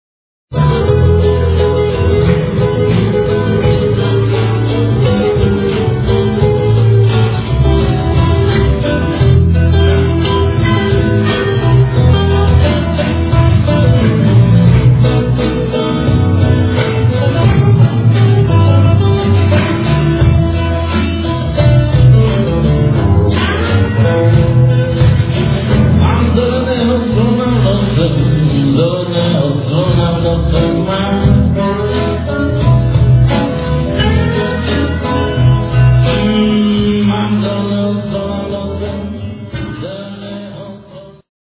BLUES
Live from Lipnice [2001].